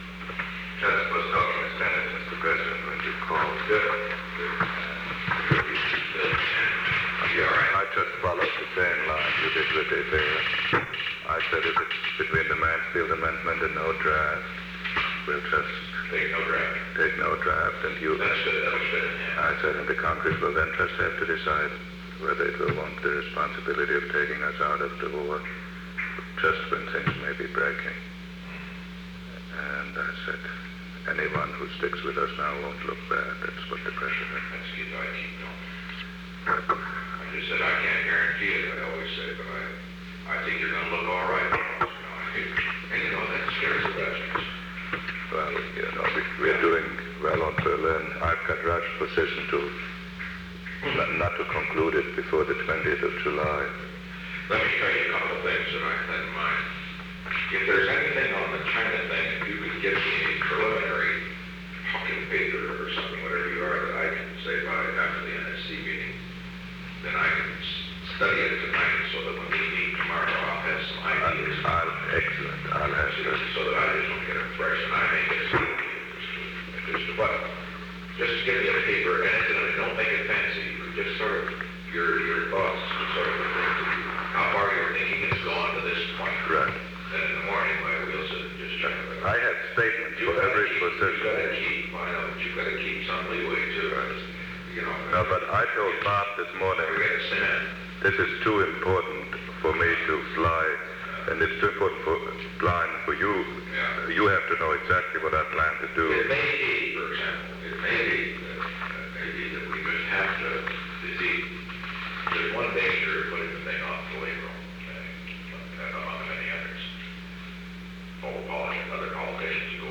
Conversation No. 532-11 Date: June 30, 1971 Time: 10:18 am - 10:30 am Location: Oval Office The President met with Henry A. Kissinger.
Secret White House Tapes